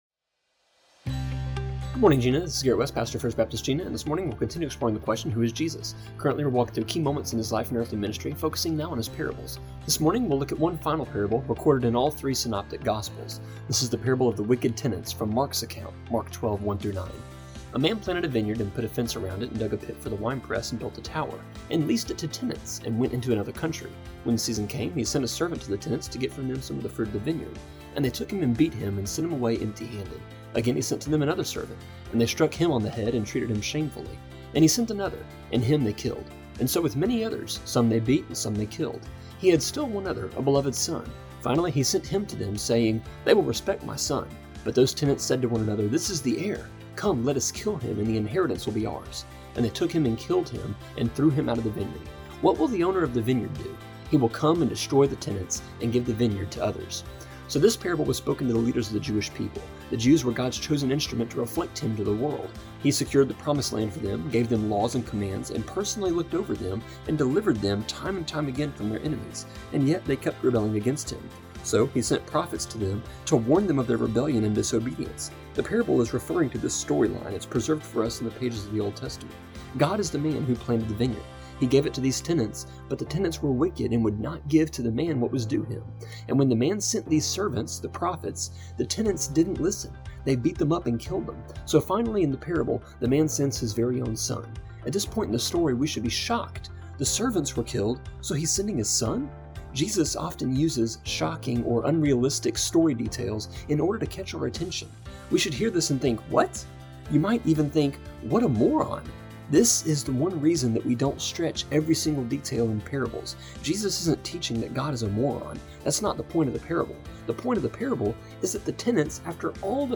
A three minute (-ish) devotion that airs Monday through Friday on KJNA just after 7am. Tune in on the radio or online for a deep dose of Bible in a three minute package!